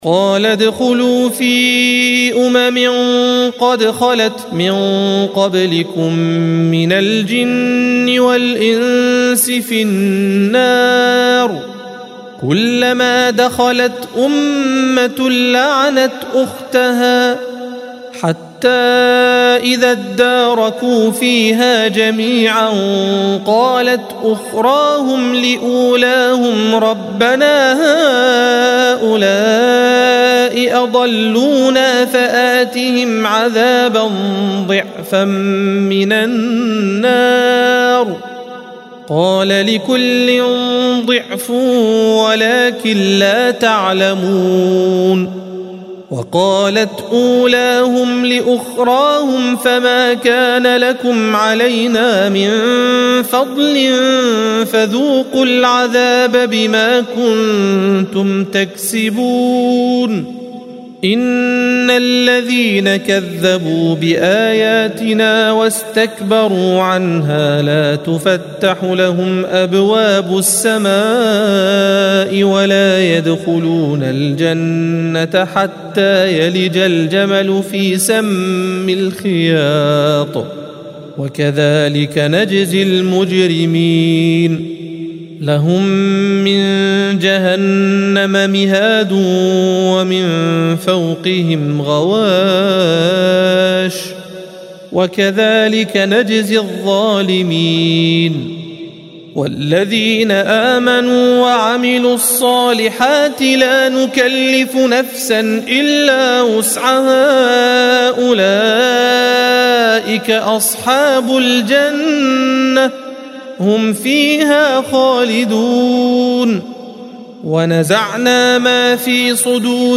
الصفحة 155 - القارئ